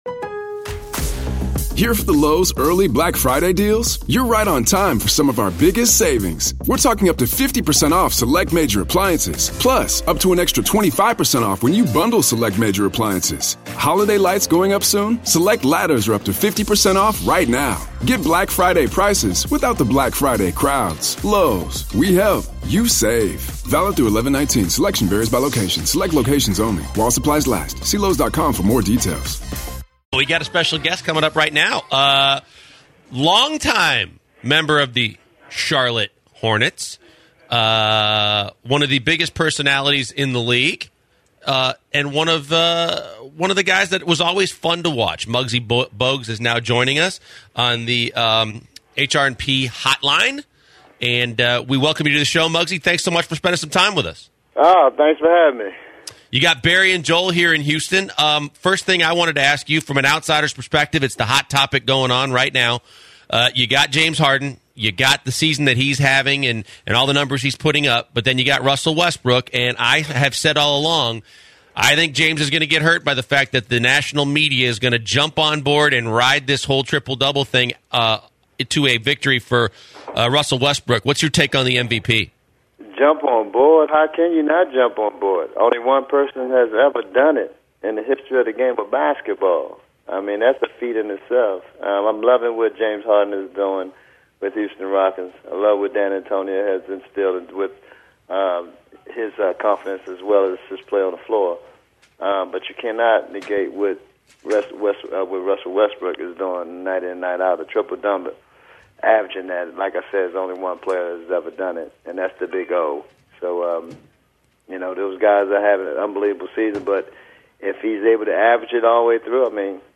Retired NBA Basketball player Muggsy Bogues joins the show to speak about the MVP race, his early baskeyball days, and today's NBA game.